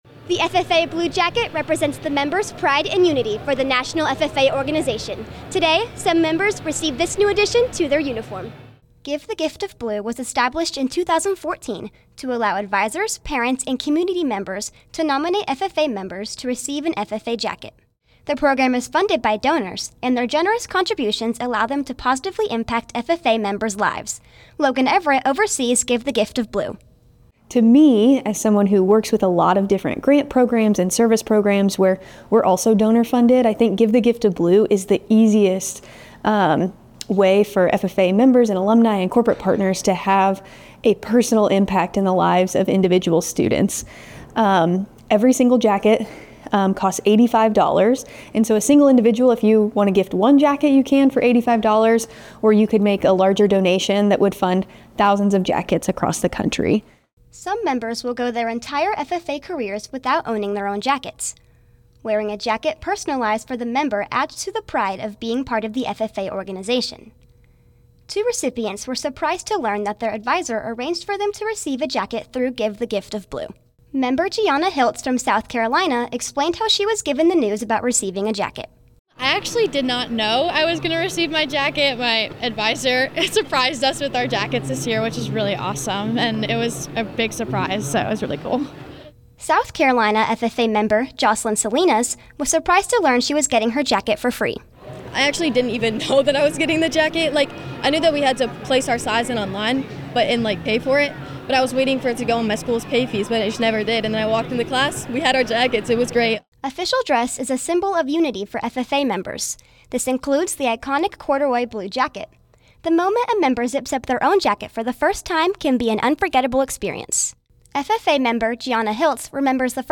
NRV24_-022_give-the-gift-of-blue_RADIO.mp3